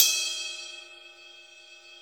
CYM RIDE 1.wav